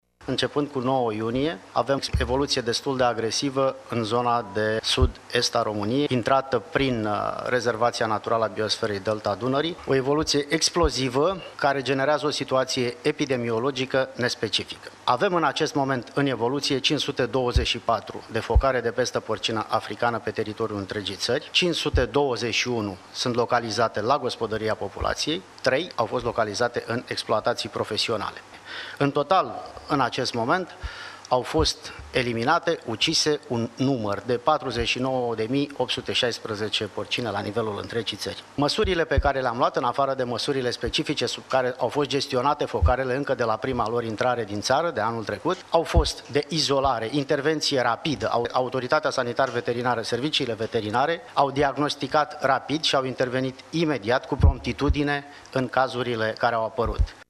Pesta porcină a avut o evoluţie agresivă, explozivă începând cu 9 iunie a declarat preşedintele ANSVSA, Geronimo Răducu Brănescu: